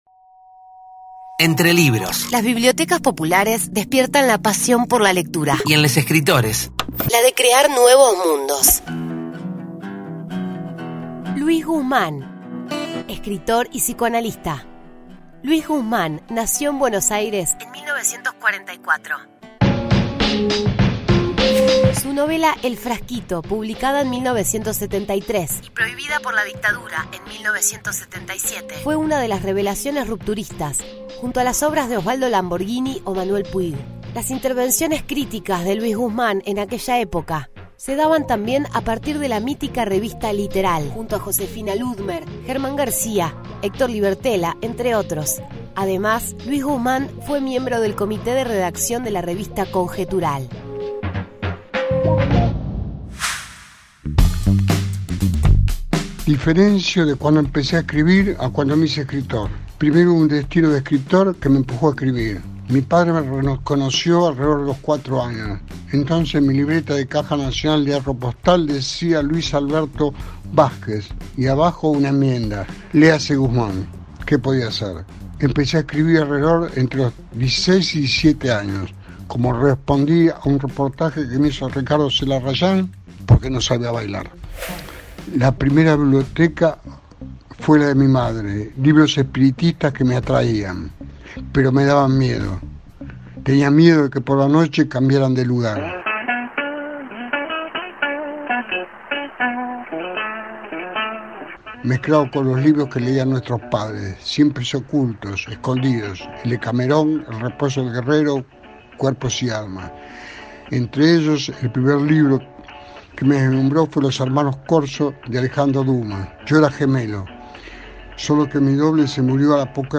Download: ENTRE LIBROS LUIS GUSMAN.mp3 Hoy en Radio BePé charlamos con el escritor y ensayista Luis Gusmán.